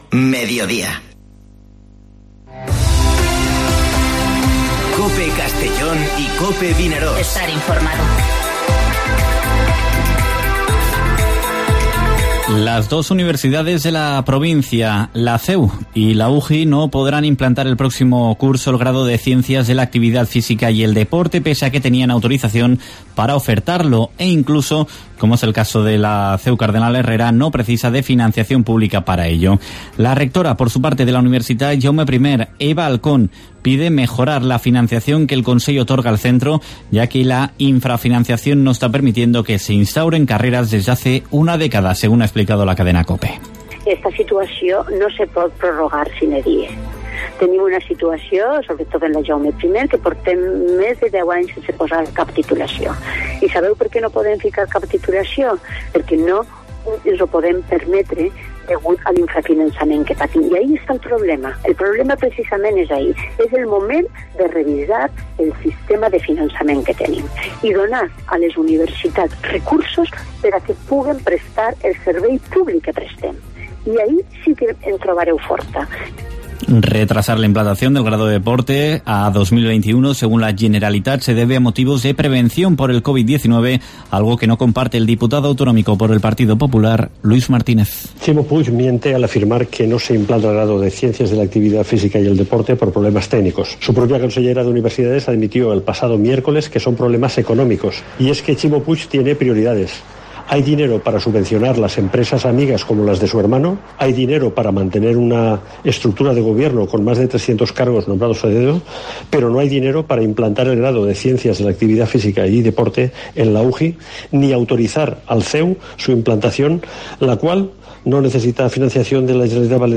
Informativo Mediodía COPE en la provincia de Castellón (08/06/2020)